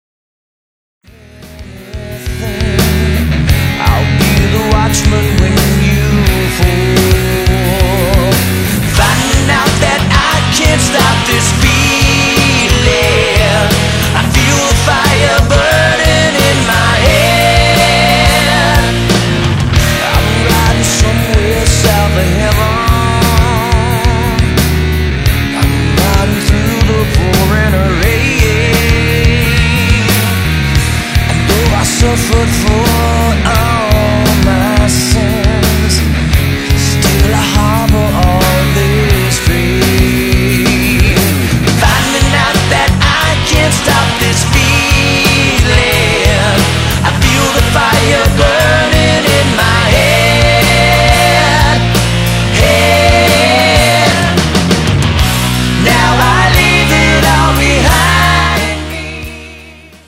Guitar & Lead Vocals
Guitar, Keyboards & Vocals
Drums, Percussion & Vocals
Bass & Vocals
classic melodic rock albums